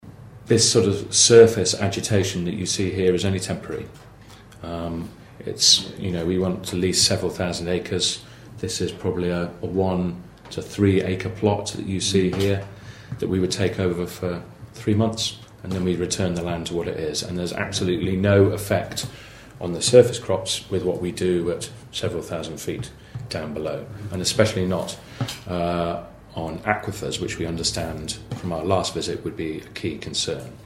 Representatives from Snowfox Discovery, a natural hydrogen exploration company, appeared before the Audubon County Board of Supervisors on Tuesday afternoon to outline their plans.